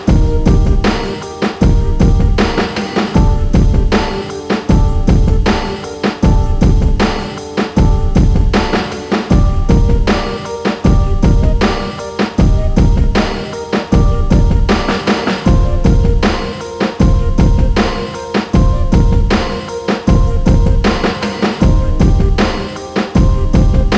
no Backing Vocals R'n'B / Hip Hop 4:16 Buy £1.50